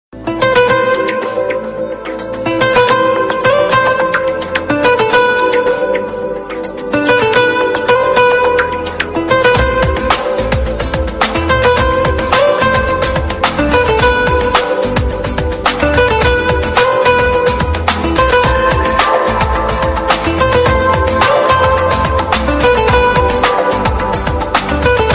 • Качество: 320, Stereo
гитара
спокойные
без слов
инструментальные
Приятная и нежная игра на гитаре